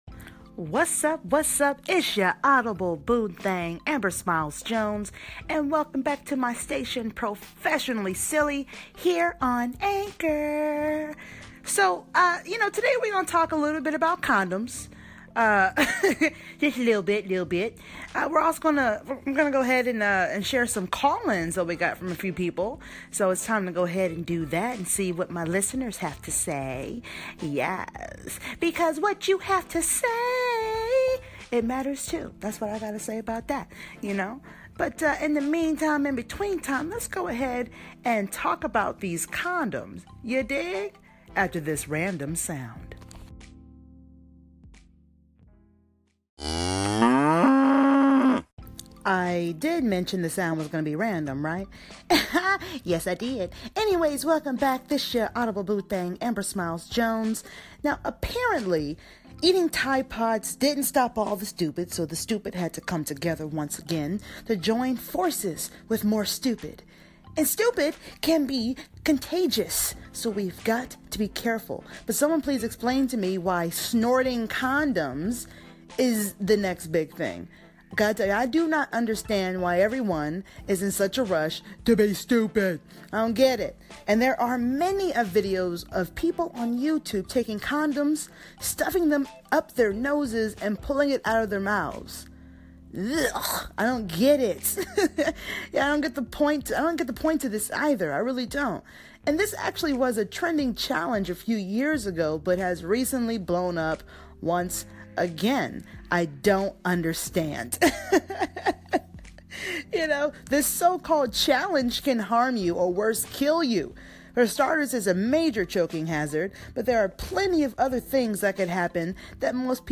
Condom Snorting Challenge & Call-ins